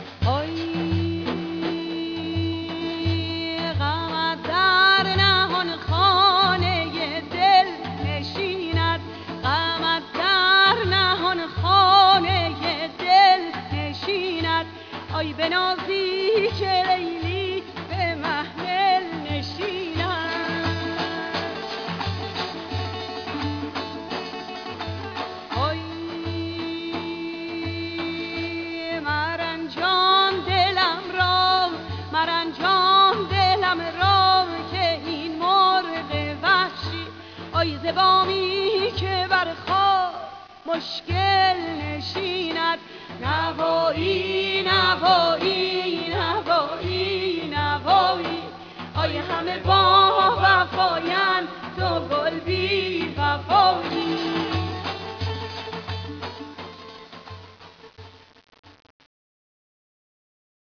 Dotar
Daf